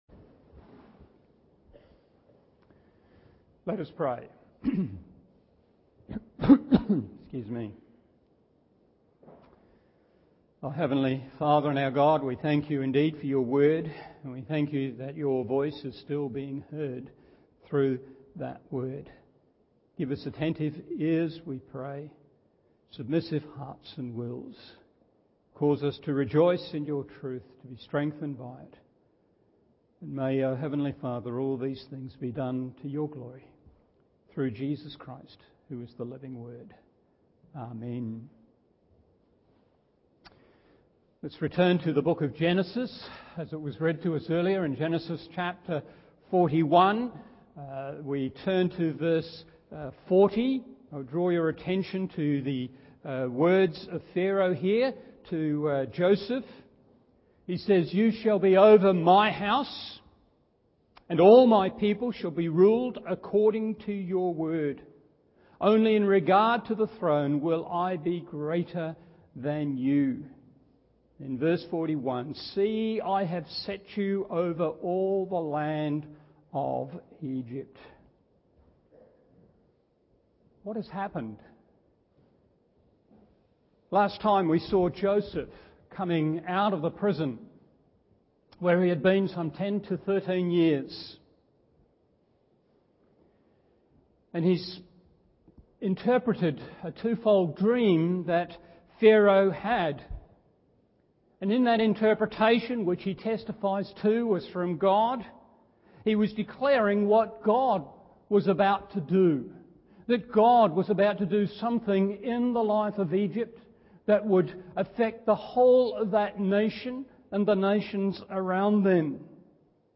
Morning Service Genesis 41:37-57 1.